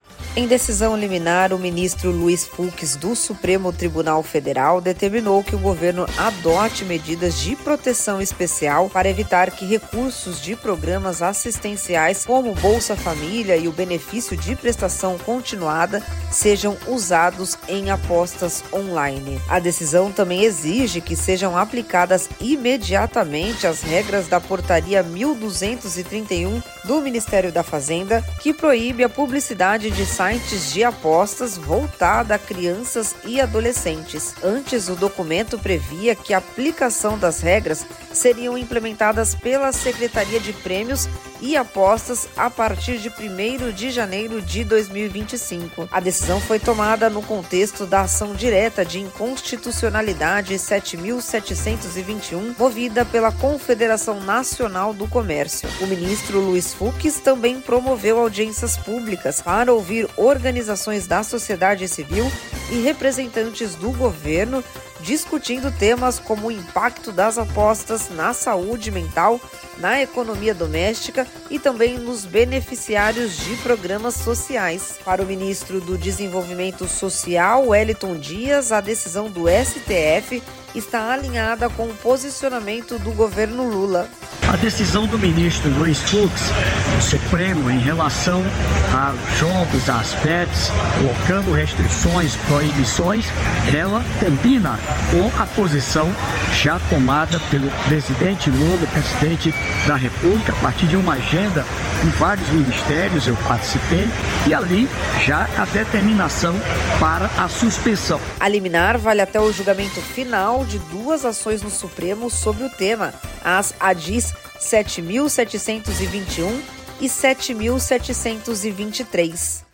INFORMATIVO: